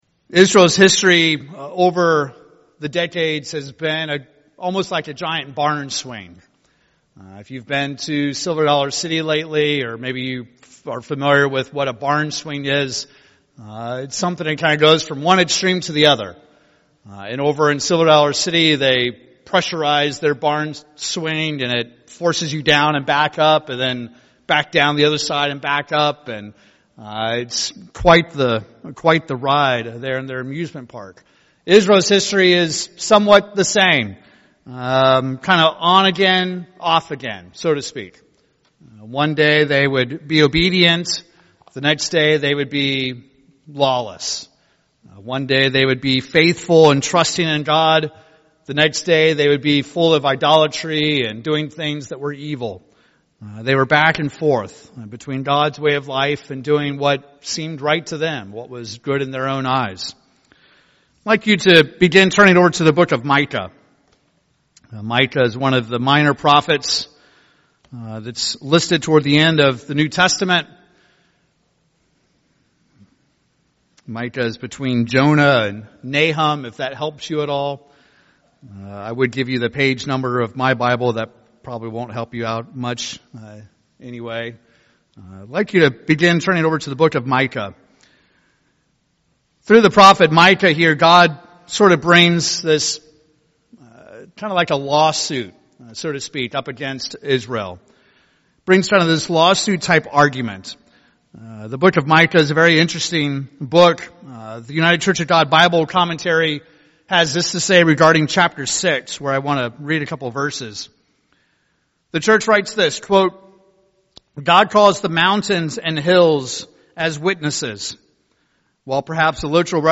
In this sermon we will examine the weightier matters of the law; Judgment, Mercy, and Faith.